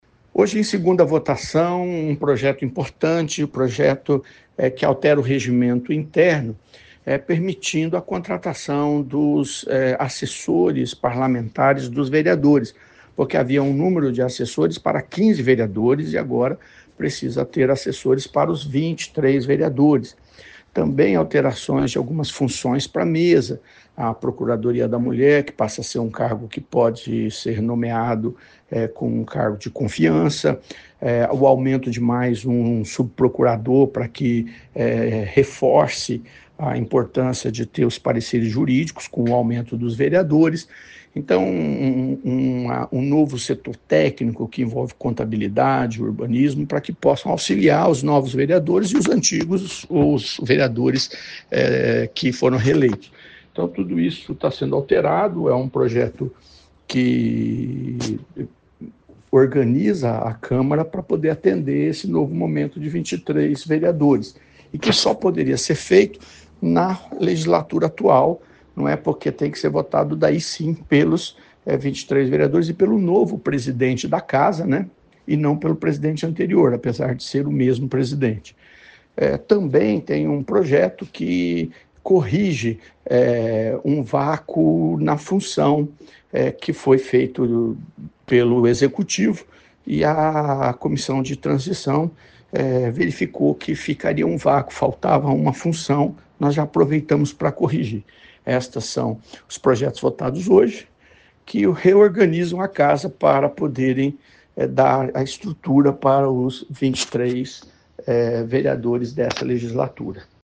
Ouça o que diz o 1º vice-presidente da Mesa Diretora, vereador Sidnei Telles: